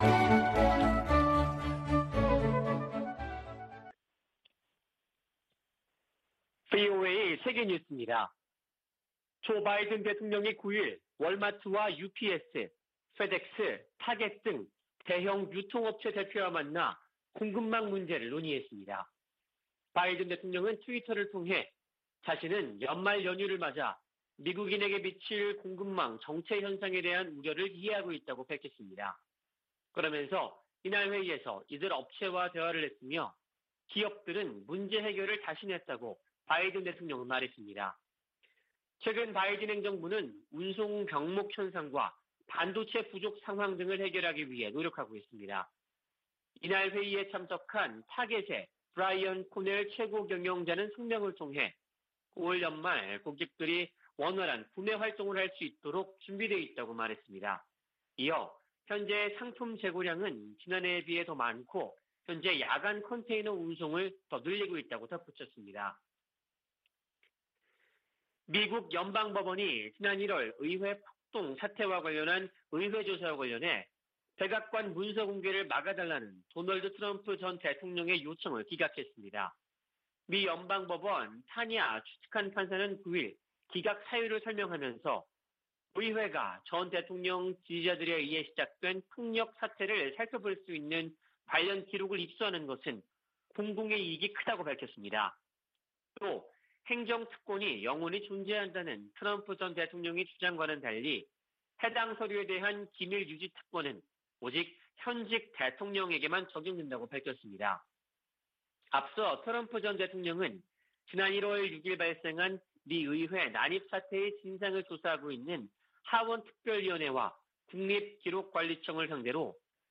VOA 한국어 아침 뉴스 프로그램 '워싱턴 뉴스 광장' 2021년 11월 11일 방송입니다. 미 국방부가 북한 문제에 중국의 역할을 다시 강조하며 유엔 안보리 제재 실행을 촉구했습니다. 미 의회의 올해 공식 의정활동이 다음달 중순 종료되는 가운데, 총 11건의 한반도 법안과 결의안이 상·하원에 계류 중입니다. 북한 당국이 김정은 국무위원장 집권 기간 시장 활동을 제도화하면서 당-국가의 정치적 통제를 강화하는 정치·경제 모델을 추구해왔다는 분석이 나왔습니다.